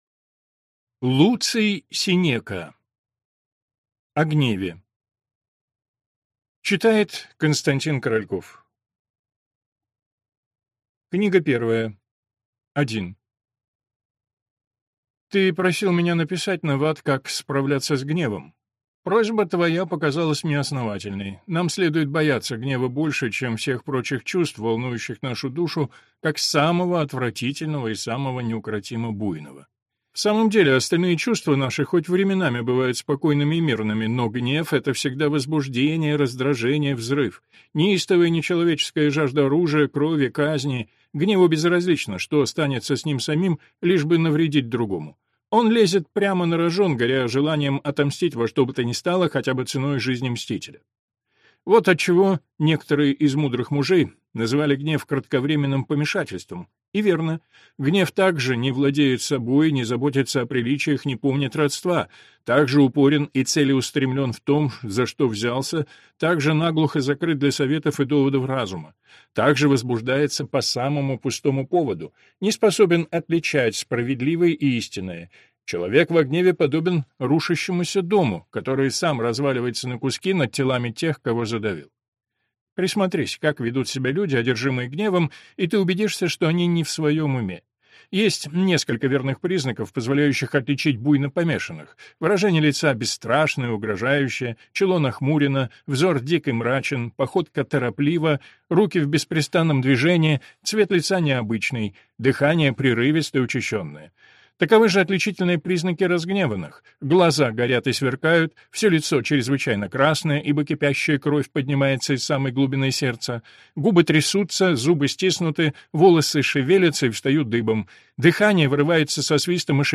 Аудиокнига О гневе | Библиотека аудиокниг